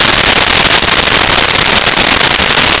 Sfx Surface Water Loop
sfx_surface_water_loop.wav